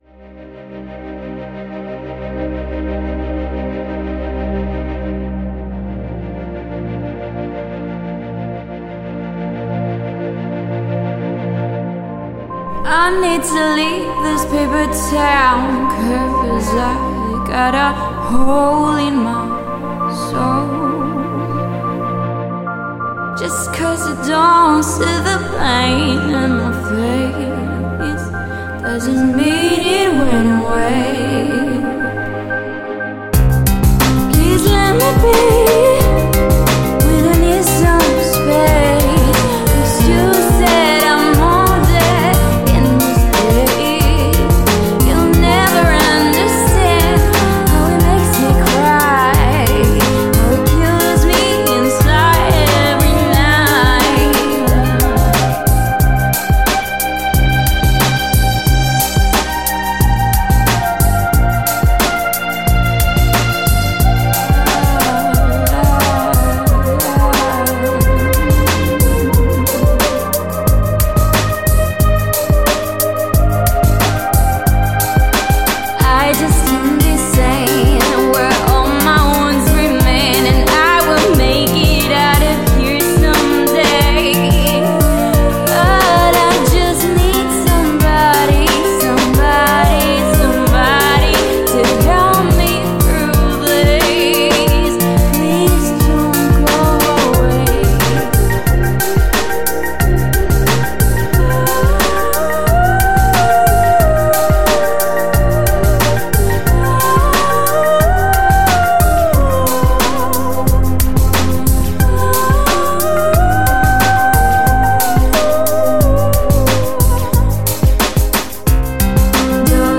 # Electronic-Pop